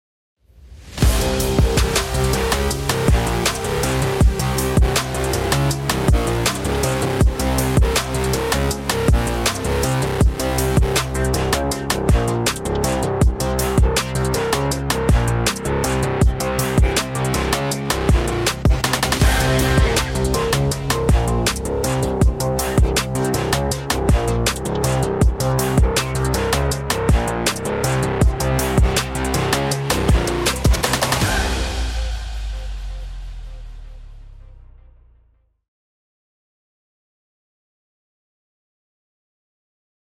VW GOLF 8R 2.0TSi 235kW Catback system ø76mm, centre pipe Gr.N, duplex rear silencer with integrated valves and additional kit for valves control.